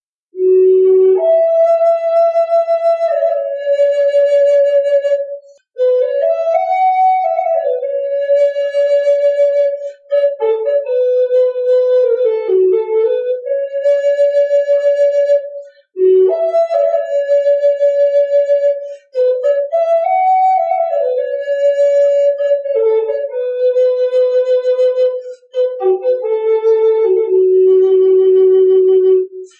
雷霆.在。Azaruja.1号。2003年8月
描述：这个闪电离我只有几米远！试图在葡萄牙Azaruja乡下录制一场漂亮的干燥的夏季风暴。相位效应来自我所站的外面门廊的墙壁。是一次难忘的经历，很幸运能录制下来！索尼TCDD7 DAT与立体声松下WVMC12麦克风。
标签： 雷击 暴风 迅雷 罢工
声道立体声